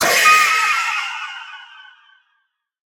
Minecraft Version Minecraft Version snapshot Latest Release | Latest Snapshot snapshot / assets / minecraft / sounds / mob / allay / death2.ogg Compare With Compare With Latest Release | Latest Snapshot
death2.ogg